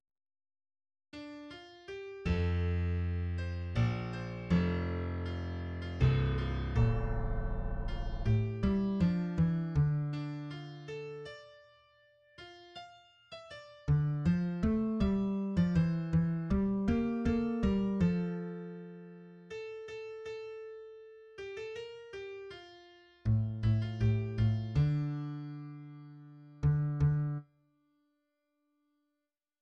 {\clef bass \tempo 4=90 \time 3/4 \set Score.currentBarNumber = #1 \bar "" a,8 r4 a,8 c d e4. g8 a g e4 c8. c16 e8 c e4. c8 d b, a,4 r8 a, c e a,4. e8 d8. d16 c4 b, g8. c'16 b8(a g) f e d c2 }\addlyrics {\set fontSize = #-2 - NIT ZUKH MIKH VU DI MIR- TN GRI- NEN GE- FINST MIKH DOR- TN NIT, MAYN SHATS. VU LE- BNS VEL- KN BAY MA- SHI- NEN, DOR- TN IZ- MAYN RU- E PLATS. } \midi{}